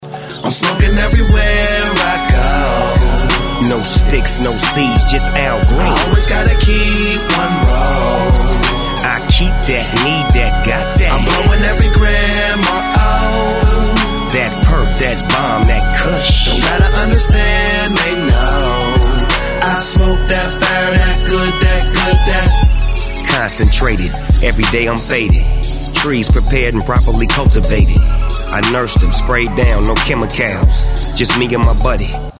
Категория: Клубные рингтоны